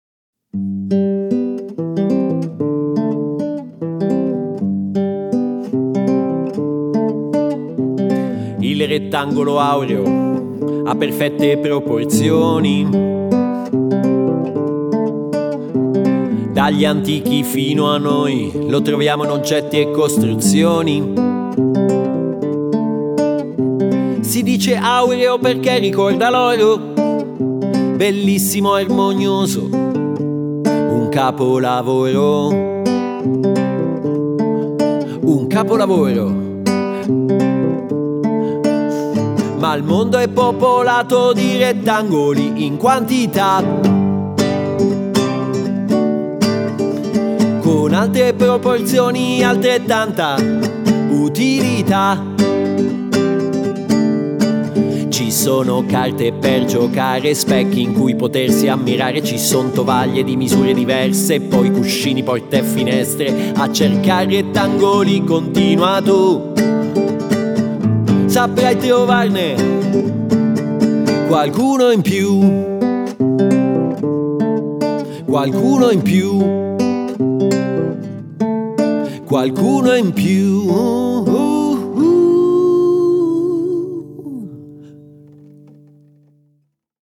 chitarra